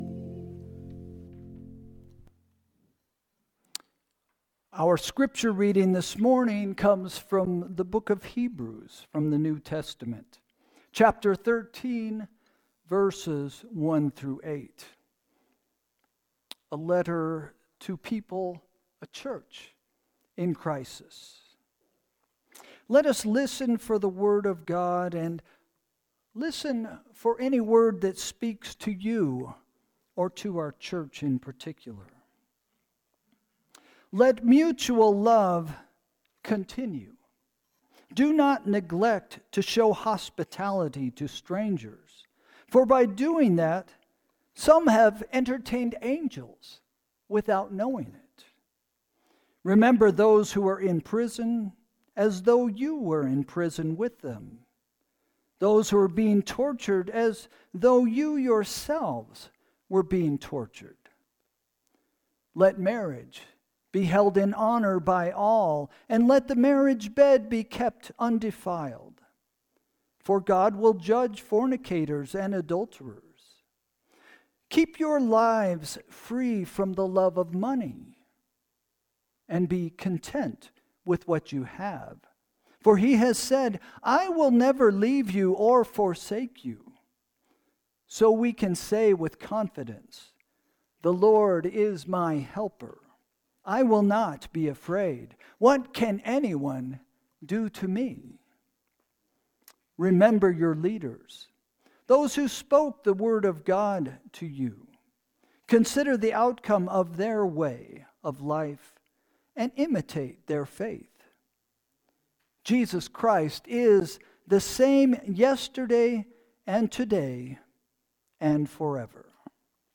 Sermon – December 1, 2024